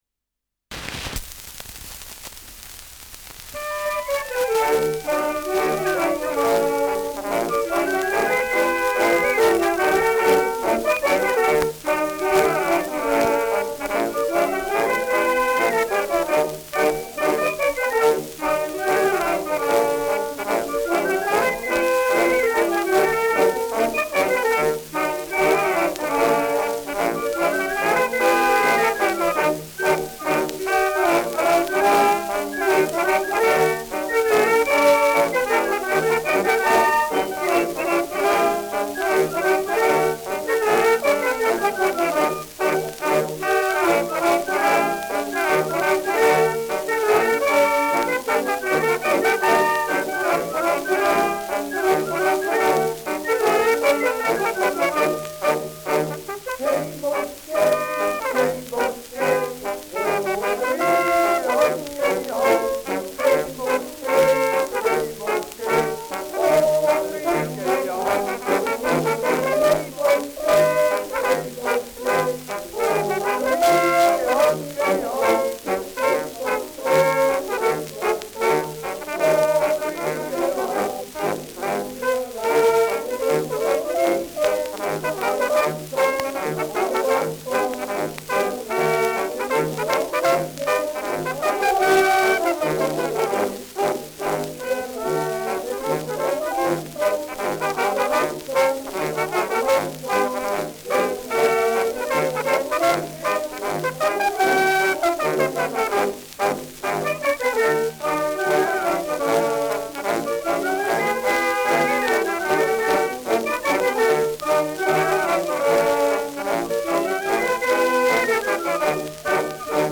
Rheinländer mit Gesang
Schellackplatte